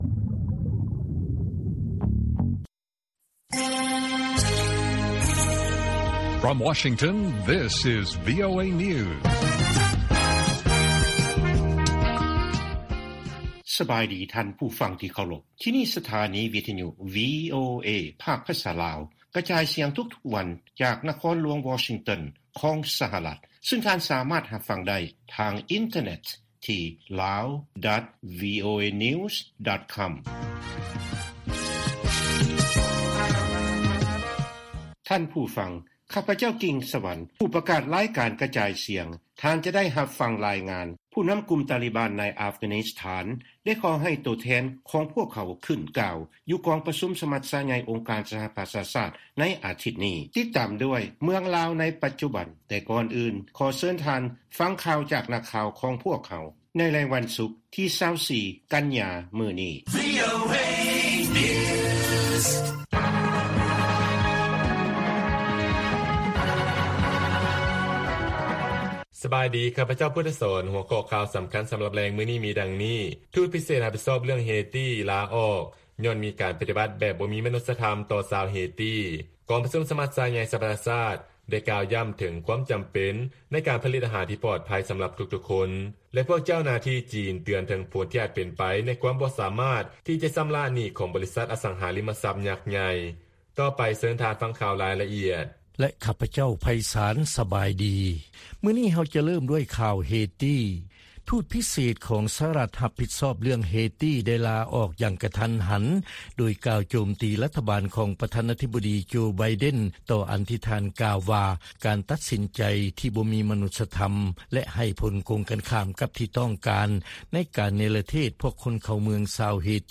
ລາຍການກະຈາຍສຽງຂອງວີໂອເອ ລາວ: ທູດພິເສດສະຫະລັດ ຮັບຜິດຊອບເລື້ອງເຮຕີ ລາອອກຍ້ອນ "ບໍ່ມີມະນຸດສະທໍາ" ໃນການເນລະເທດຊາວເຮຕີ.